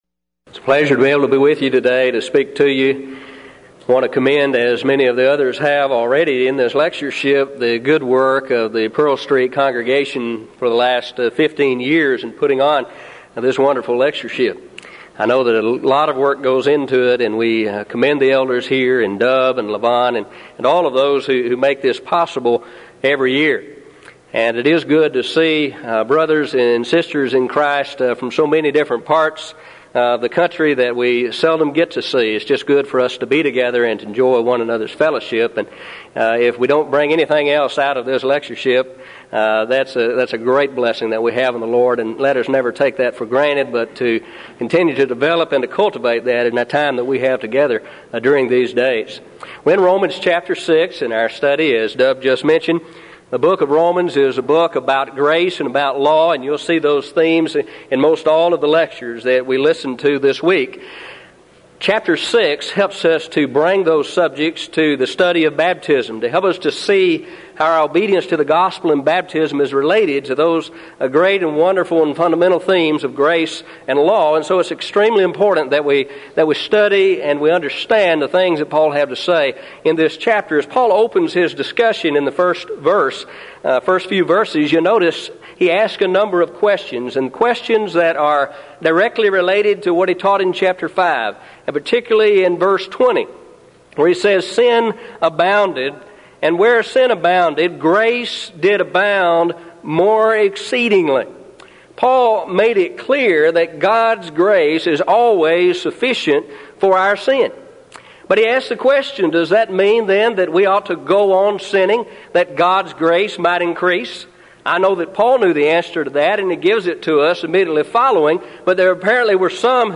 Event: 1996 Denton Lectures Theme/Title: Studies In The Book Of Romans